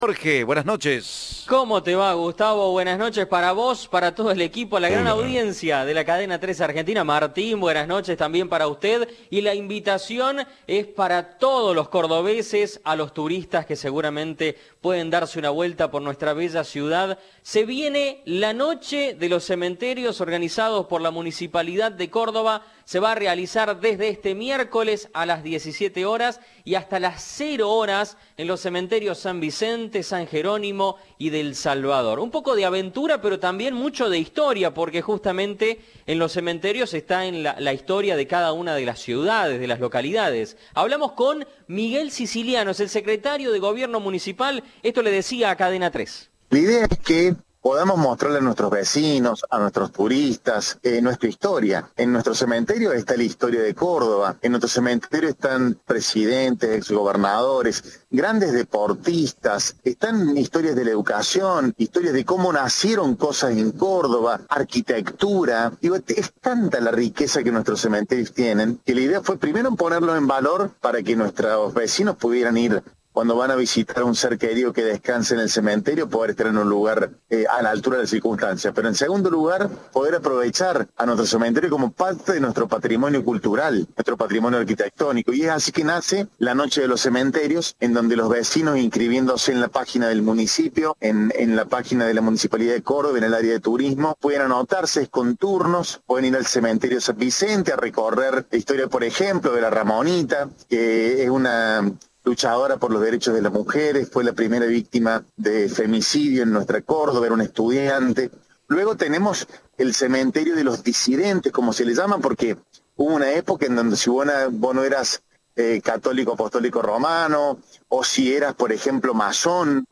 El secretario de Gobierno de la Municipalidad de Córdoba, Miguel Siciliano, dijo a Cadena 3 que la iniciativa busca "mostrar la historia y el patrimonio de la ciudad".
Informe